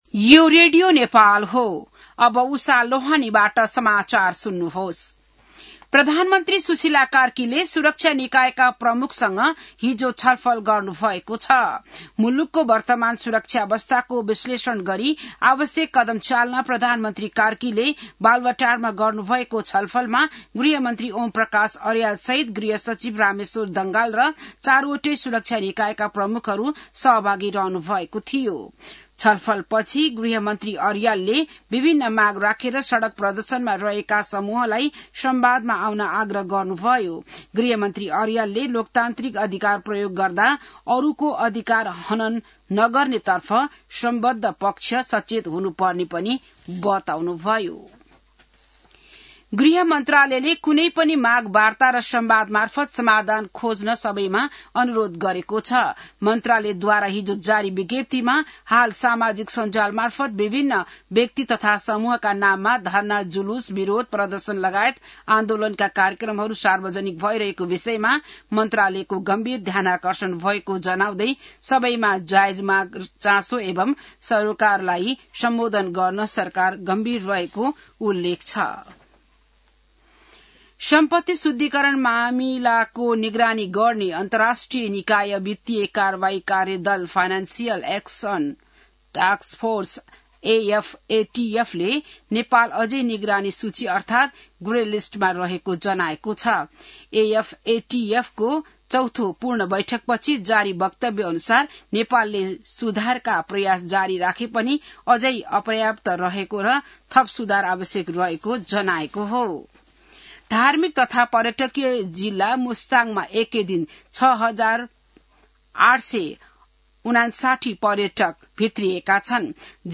बिहान १० बजेको नेपाली समाचार : १८ पुष , २०२६